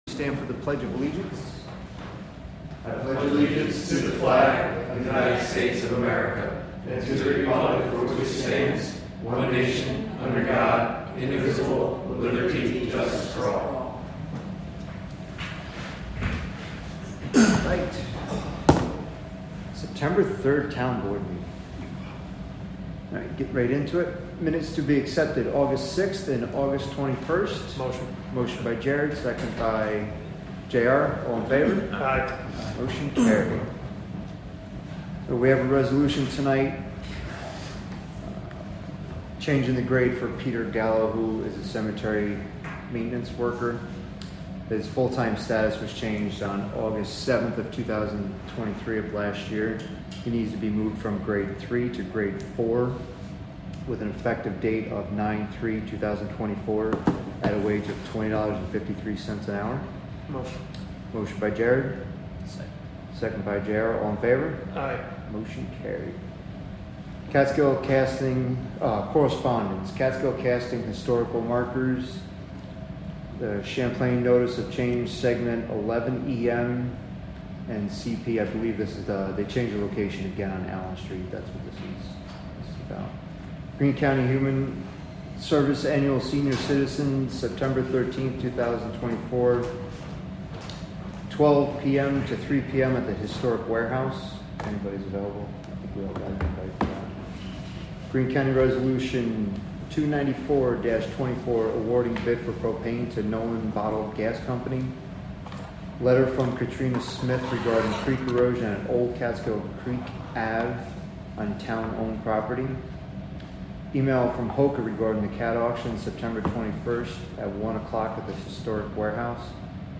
Live from the Town of Catskill: September 3, 2024 Catskill Town Board Meeting (Audio)